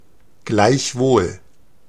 Ääntäminen
IPA : /haʊˈbiː.ɪt/